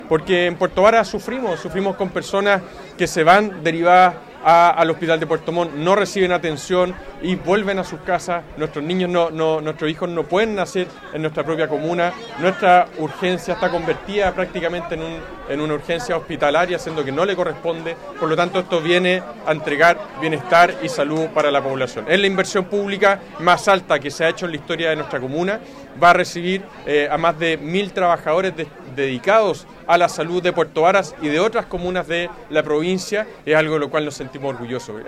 Además, el alcalde de Puerto Varas, Tomás Gárate, explicó la importancia del recinto de salud para la comuna lacustre, donde afirmó que esta obra viene a entregar bienestar y salud a la población, siendo la inversión más alta que se ha hecho en la comuna.
primera-piedra-garate.mp3